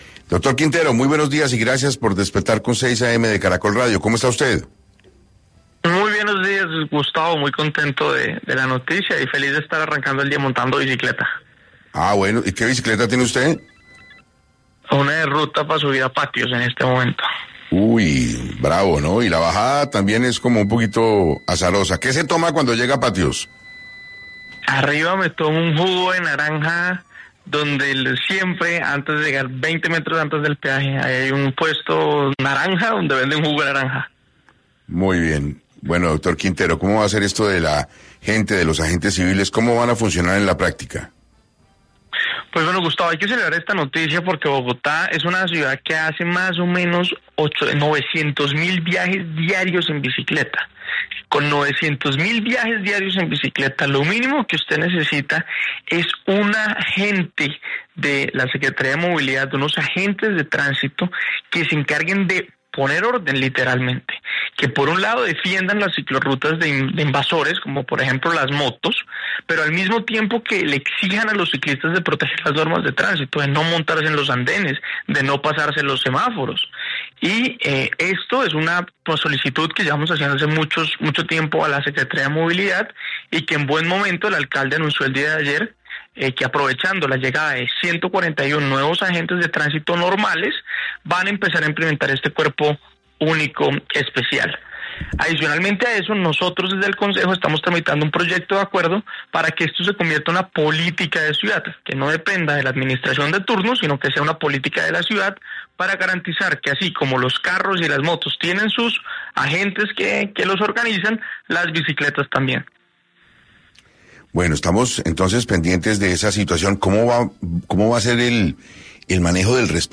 En entrevista con 6AM, el vicepresidente del Consejo, Juan David Quintero, indicó que Bogotá es una ciudad que hace cerca de 900.000 viajes diarios en bicicleta, por ello es importante tener estos guardianes de la vía.